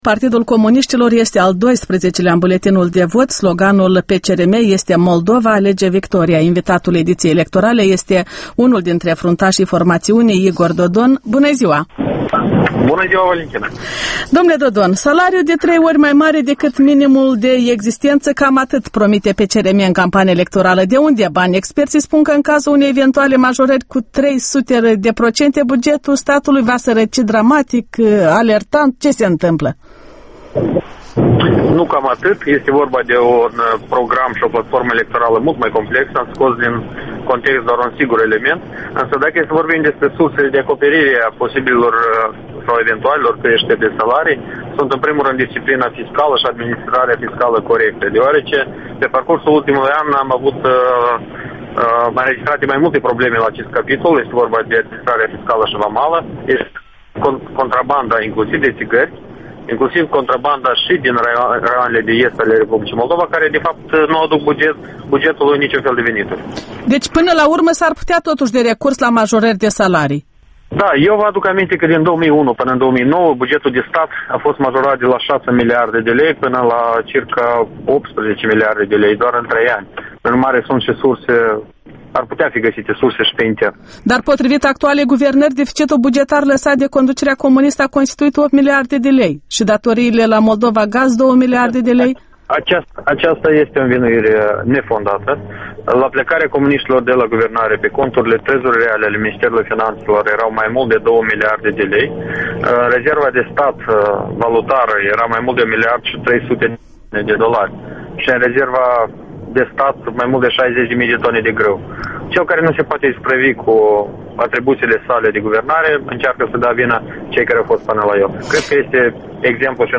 Interviu Electorala 2010: cu Igor Dodon